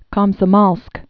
(kŏmsə-môlsk)